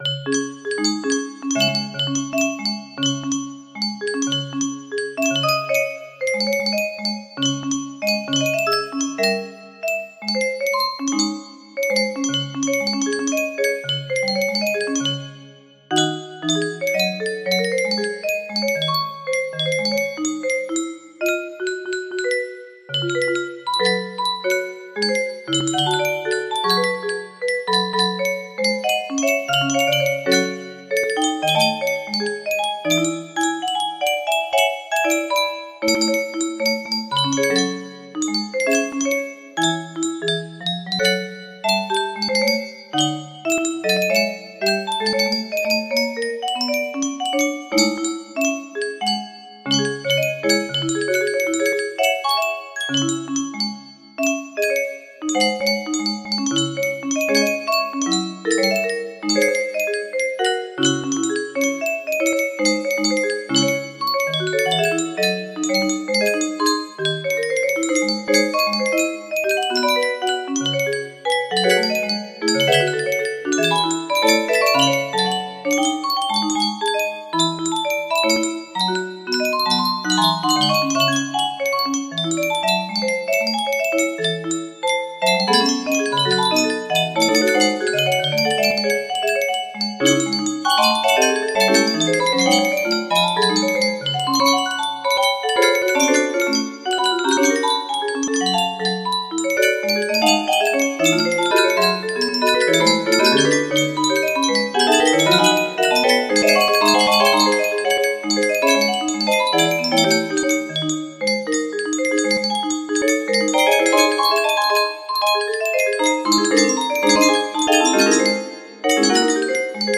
Unknown Artist - Untitled music box melody
Full range 60